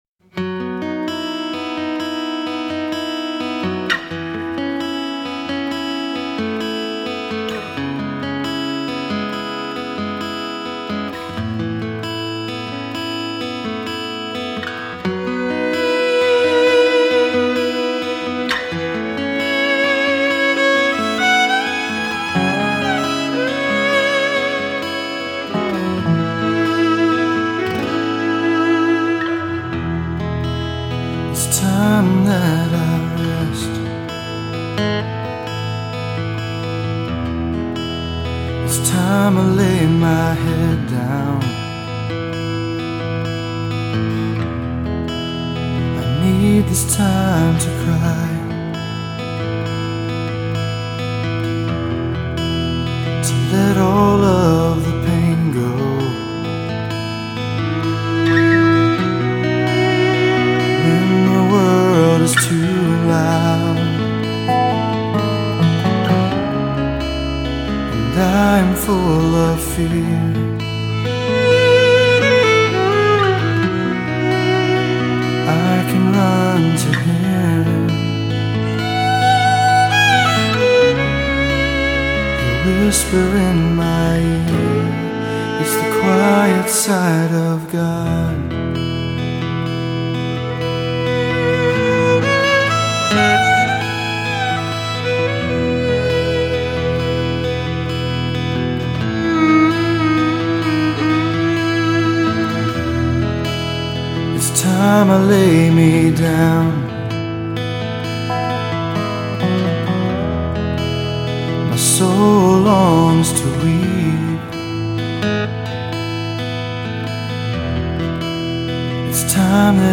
dance/electronic
Rhythm & Blues
Unplugged